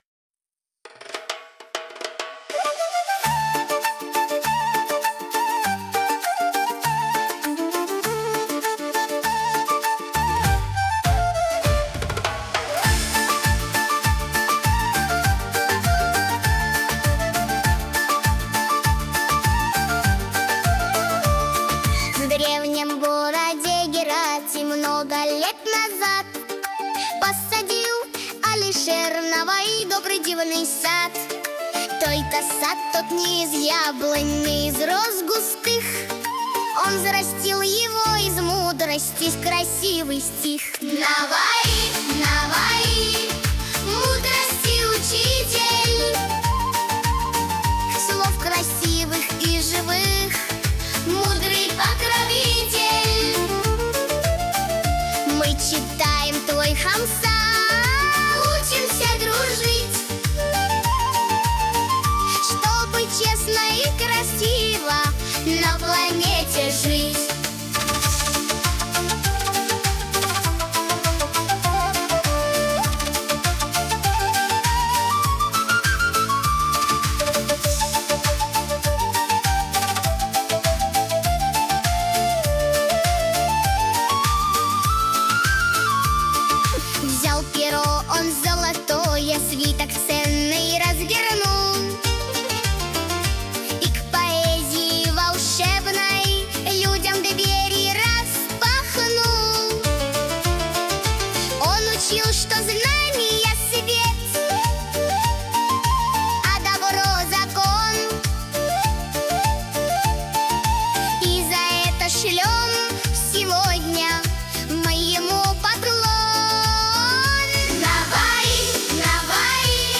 • Качество: Хорошее
• Жанр: Детские песни
Узбекские детские песни
восточные мотивы